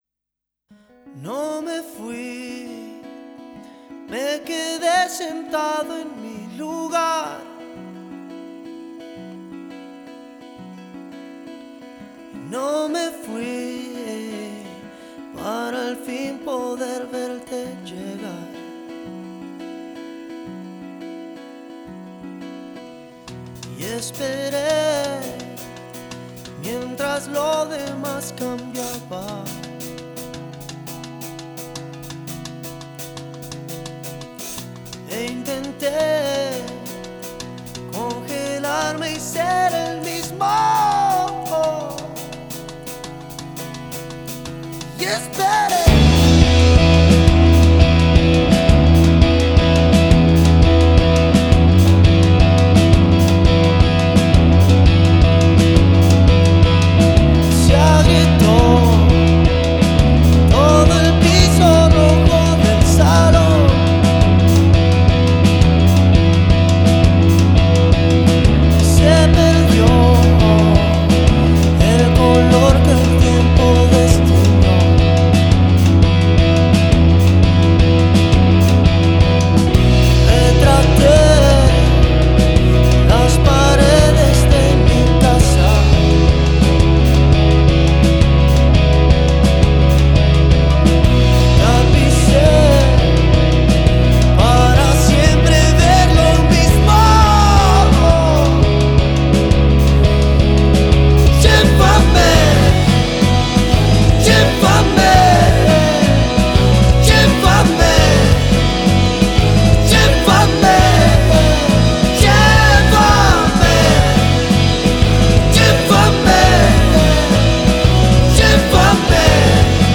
la versión con voz o